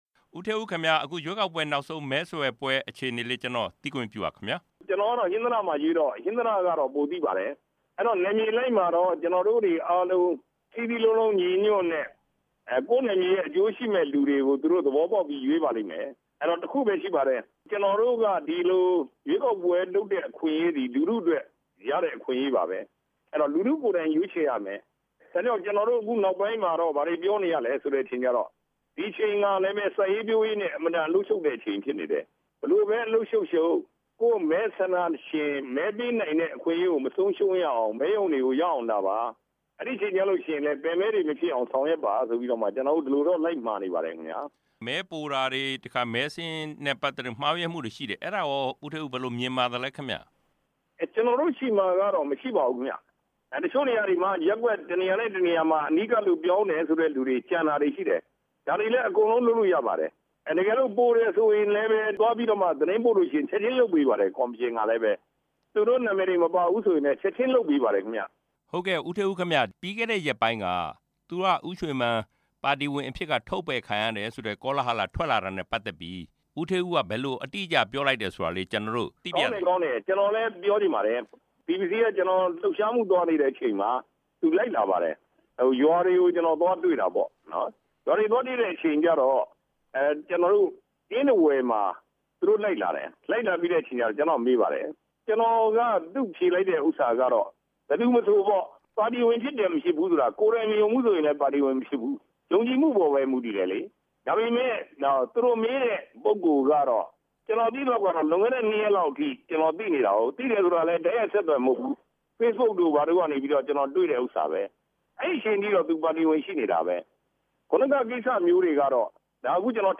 ပြည်ထောင်စုကြံ့ခိုင်ရေးနဲ့ ဖွံ့ဖြိုးရေးပါတီဟာ လာမယ့်ရွေးကောက်ပွဲမှာ ၆၅ ရာခိုင်နှုန်း ကနေ ၈ဝ ရာခိုင်နှုန်းအထိ အနိုင်ရလိမ့်မယ်လို့ ခန့်မှန်းကြောင်း ပါတီရဲ့ပူးတွဲ ဥက္ကဌ ဦးဌေးဦးက ဒီကနေ့ ပြောကြားလိုက်ပါတယ်။ ရွေးကောက် ပွဲအတွက် နောက်ဆုံးမဲဆွယ် ခွင့်ပြုတဲ့နေ့မှာ ဟင်္သာတမြို့ကို ရောက်ရှိနေတဲ့ ဦးဌေးဦးကို RFA က ဆက်သွယ် မေးမြန်းစဉ်သူက အခုလိုပြောကြားခဲ့တာဖြစ်ပါတယ်။